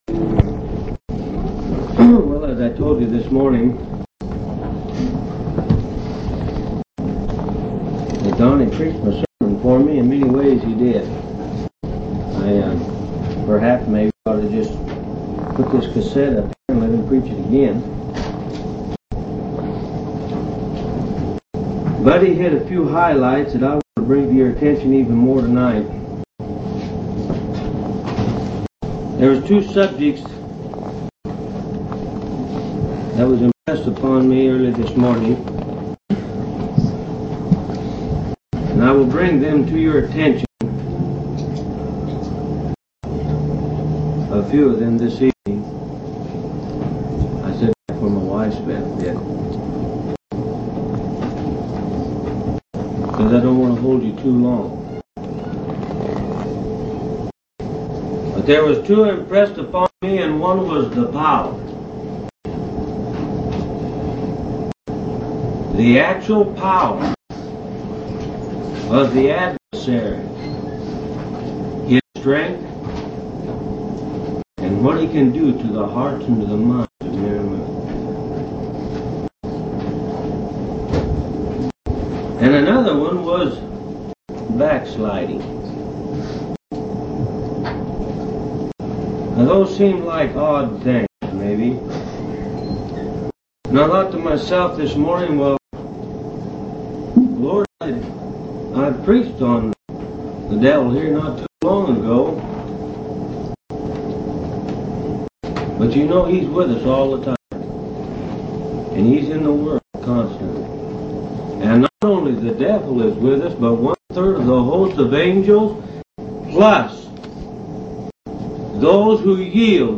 5/16/1982 Location: Phoenix Local Event